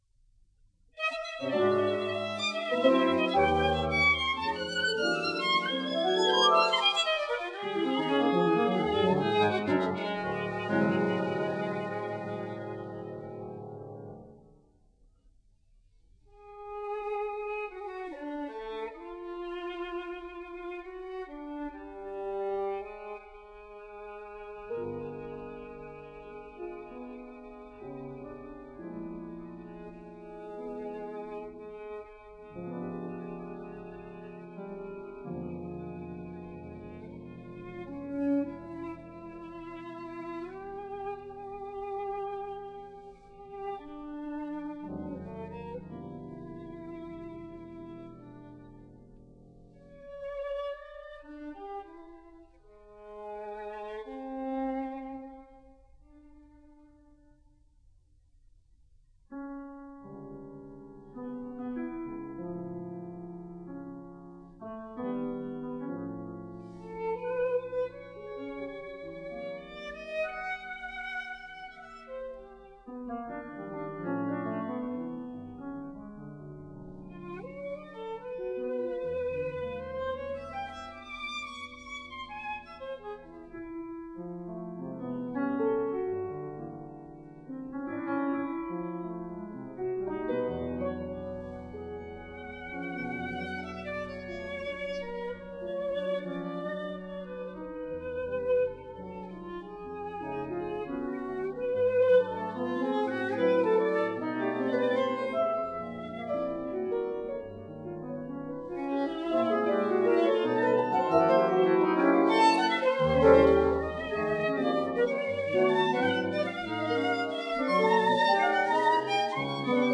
violon
piano
Enregistré au Théâtre de Poissy